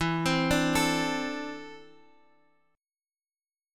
E7sus4#5 chord